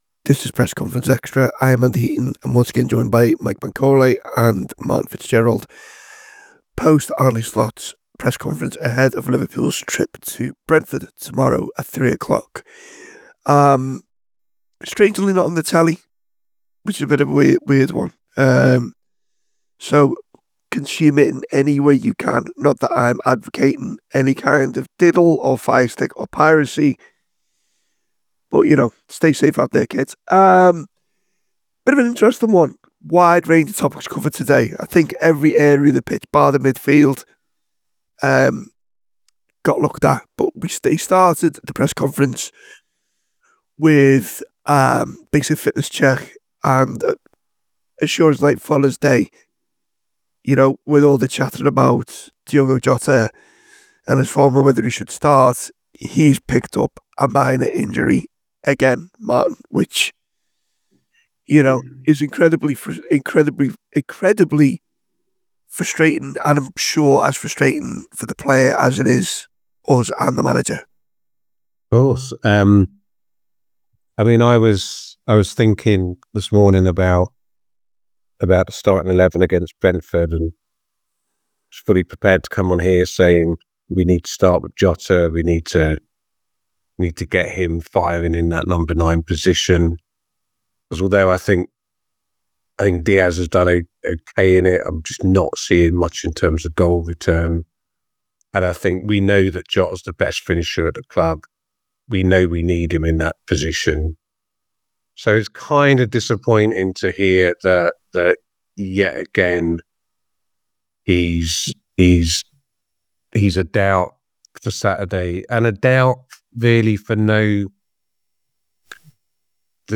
Below is a clip from the show – subscribe for more on the Brentford v Liverpool press conference…